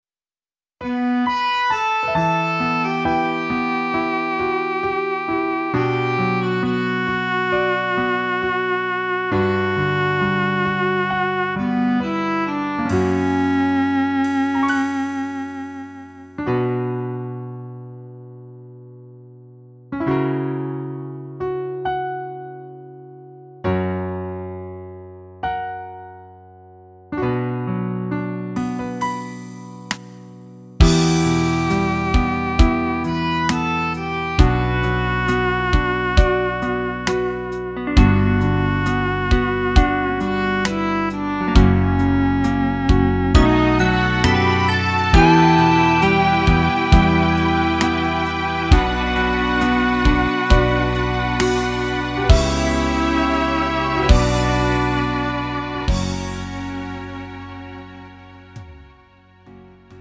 음정 -1키 3:47
장르 구분 Lite MR